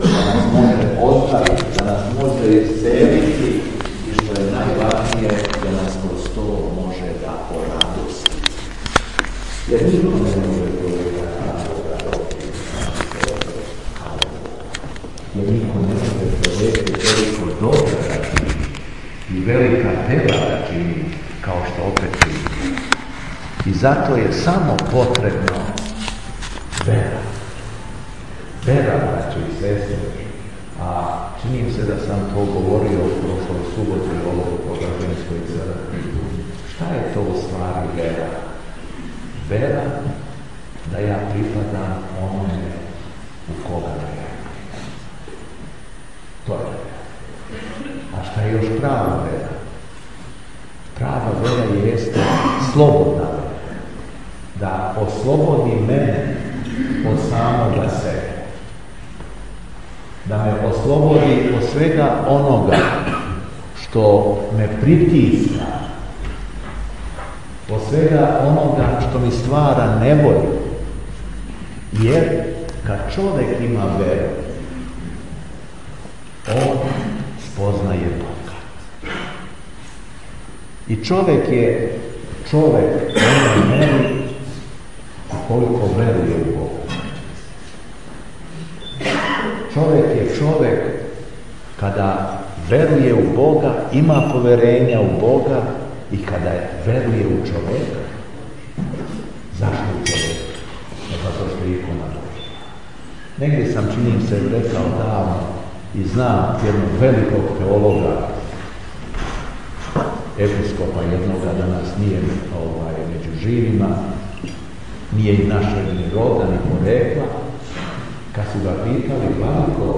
СВЕТА АРХИЈЕРЕЈСКА ЛИТУРГИЈА У ХРАМУ СВЕТЕ ПЕТКЕ У СМЕДЕРЕВСКОЈ ПАЛАНЦИ - Епархија Шумадијска
Беседа Епископа шумадијског Г. Јована